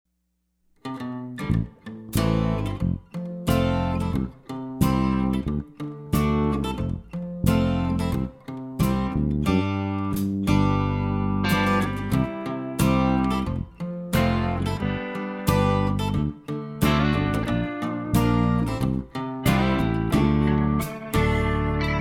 Listen to a sample of this instrumental song.
Downloadable Instrumental Track